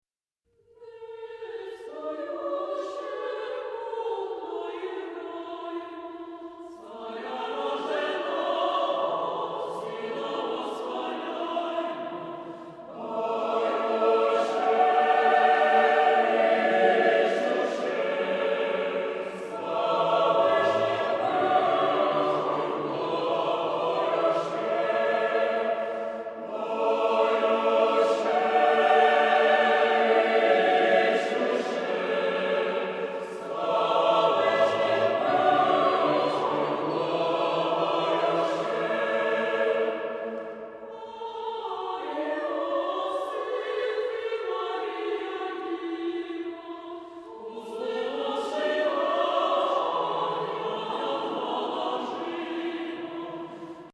Genre-Style-Forme : Carol ; Sacré ; Orthodoxe Caractère de la pièce : festif ; joyeux ; majestueux Type de choeur : SATB (4 voix mixtes )
Tonalité : la majeur